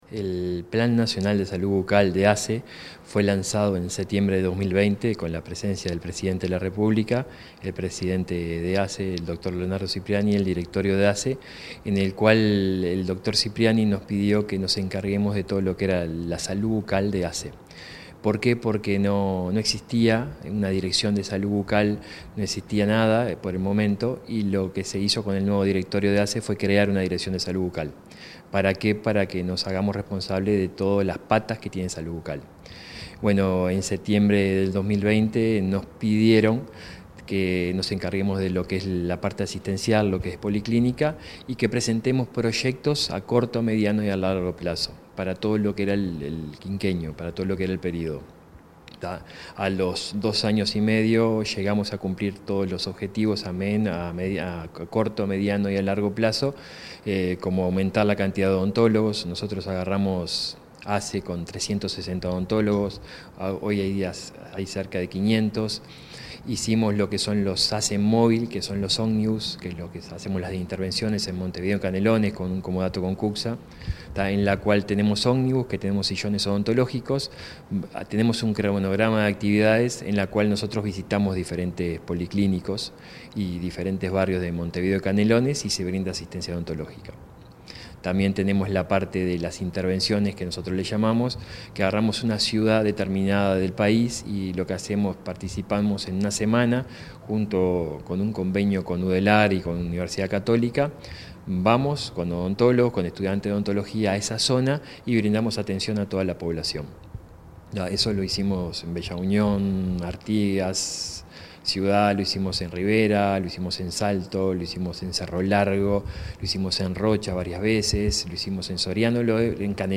Entrevista al director del Plan Nacional de Salud Bucal de ASSE, Néstor Graña